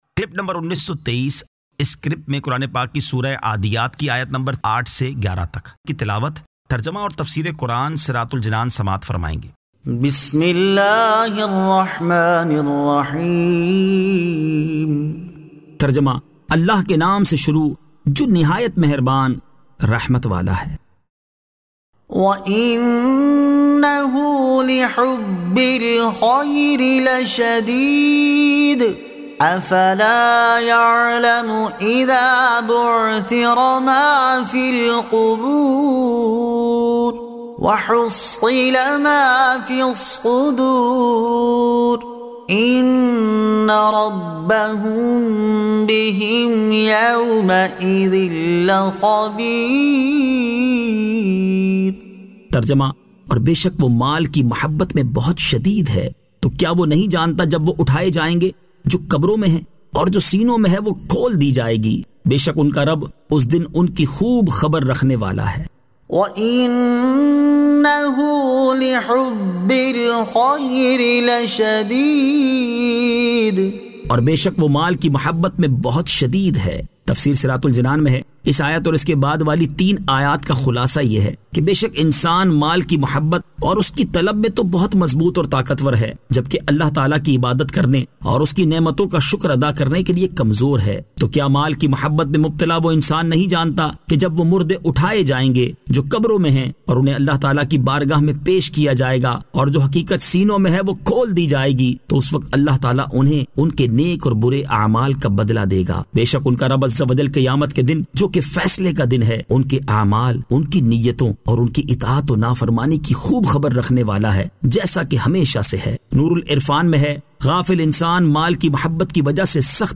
Surah Al-Adiyat 08 To 11 Tilawat , Tarjama , Tafseer